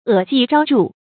恶迹昭著 è jì zhāo zhù
恶迹昭著发音